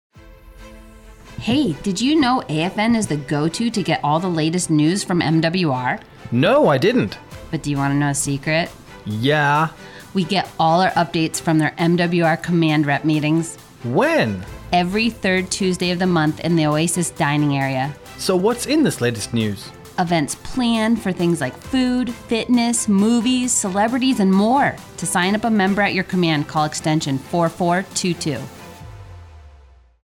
MWR Rep Meeting - Radio Spot
Thirty-second spot highlighting the MWR Rep Meetings to be aired on AFN Bahrain's morning and afternoon radio show.